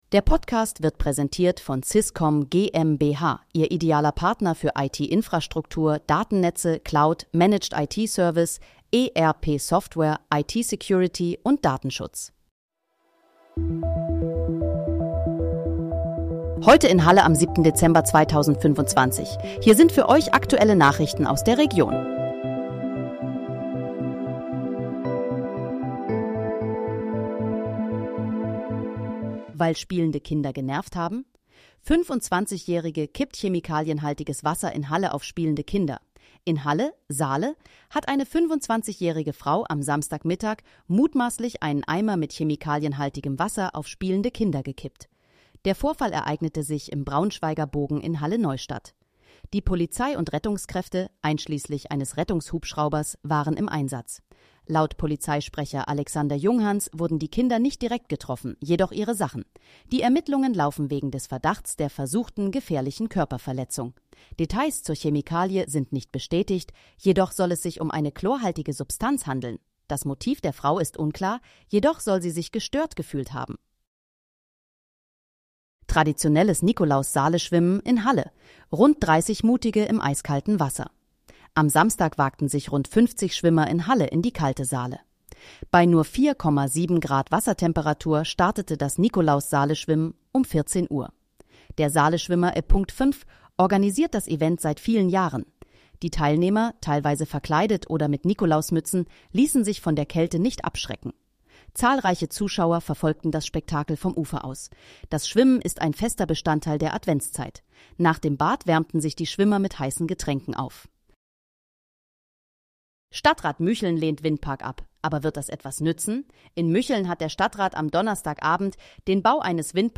Heute in, Halle: Aktuelle Nachrichten vom 07.12.2025, erstellt mit KI-Unterstützung
Nachrichten